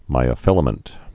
(mīə-fĭlə-mənt)